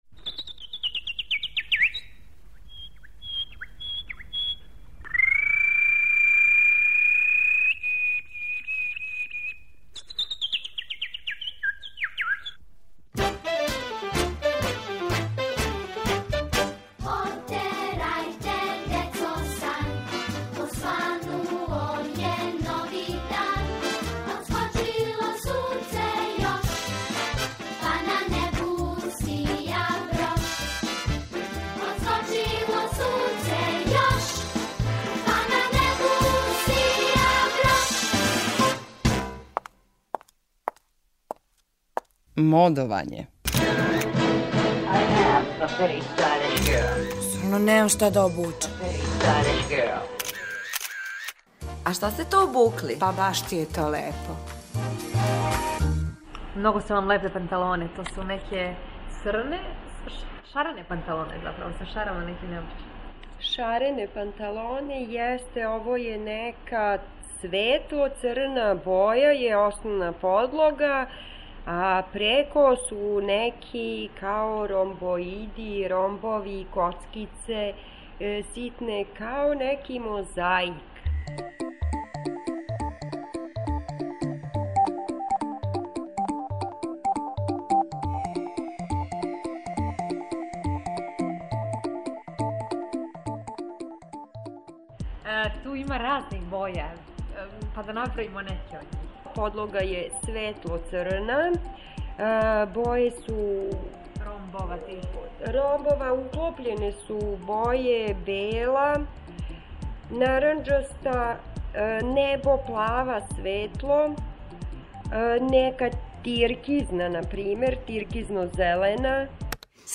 У серијалу МОДОВАЊЕ питамо занимљиво одевене случајне пролазнике да опишу своју одевну комбинацију.